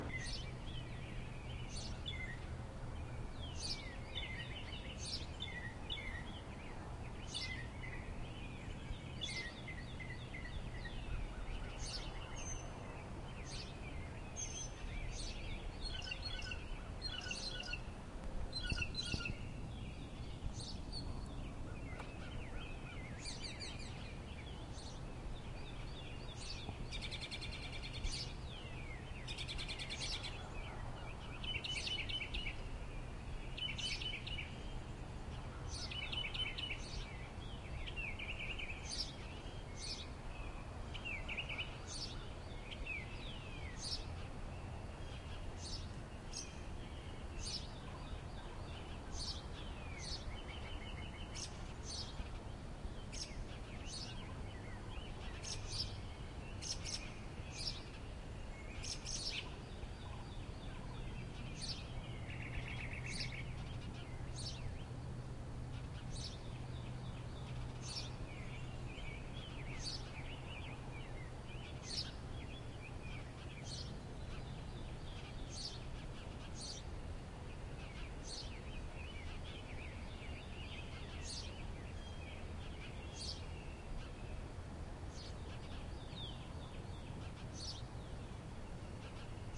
鸟鸣 " 雾鸫
描述：雾鸫在德国科隆郊区的一根天线上唱着它的悲伤歌曲。
Tag: 环境 鸟鸣 现场录音